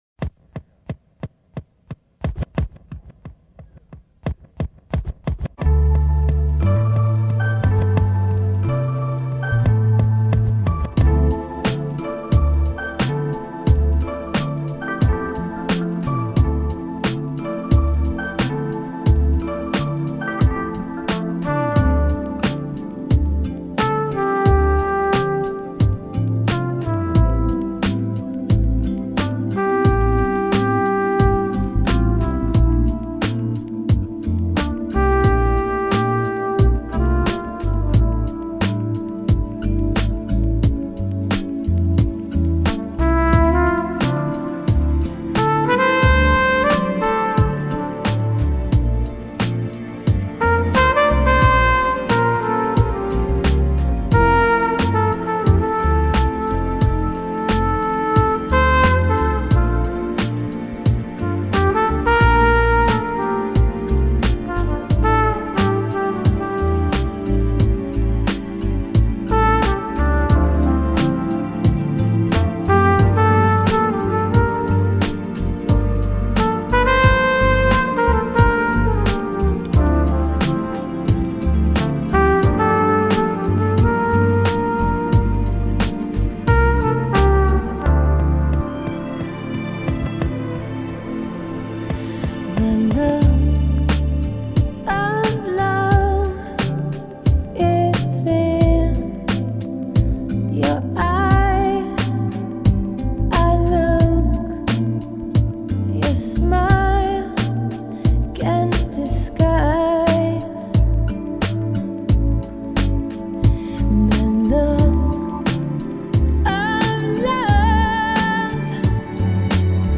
爵士小号手
小号演奏